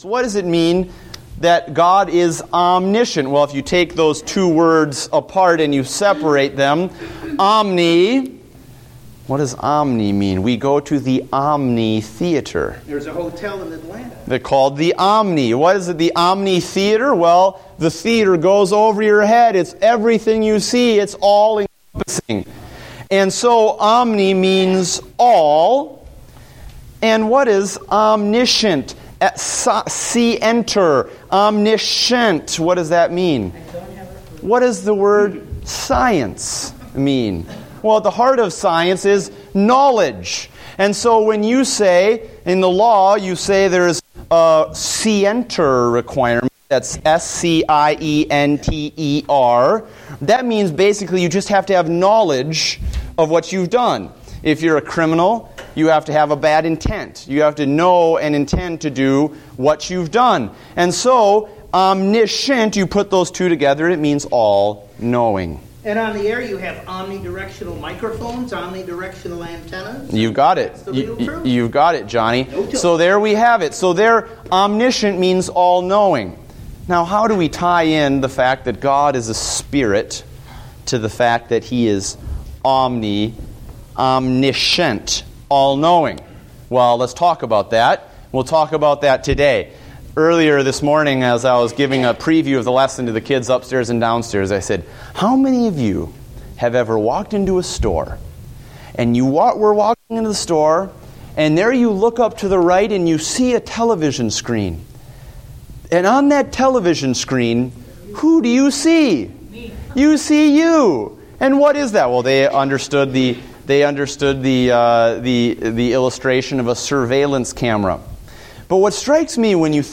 Date: November 2, 2014 (Adult Sunday School)